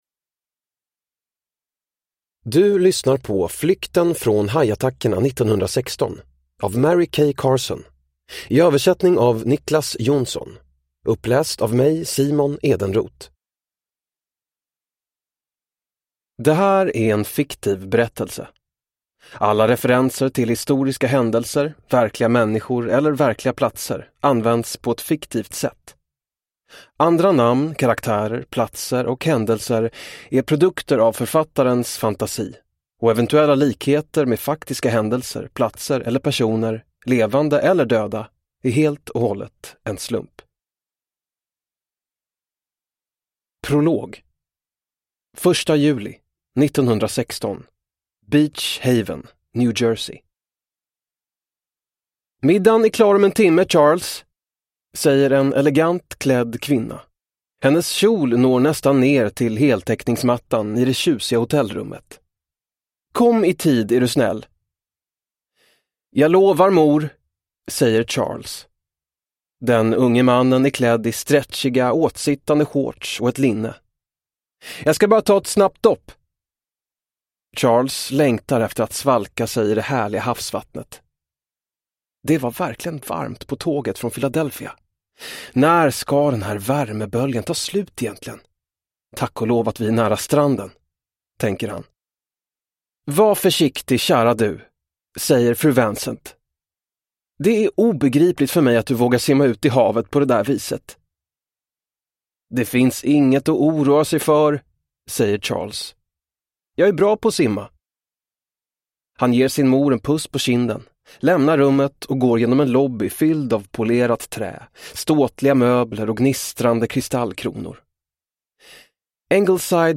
Flykten från hajattackerna 1916 (ljudbok) av Mary Kay Carson